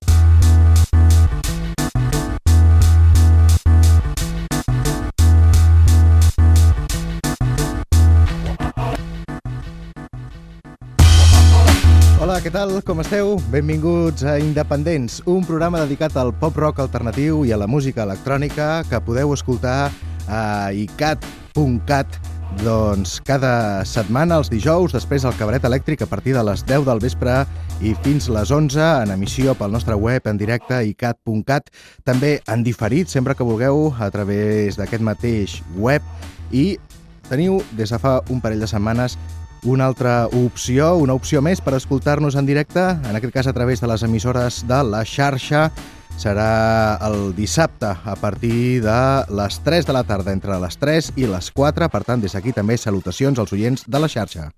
Inici i salutació als oients de La Xarxa des del programa produït per iCat per a Internet que també passava a poder ser escoltat per ràdio a les emissores de La Xarxa
Musical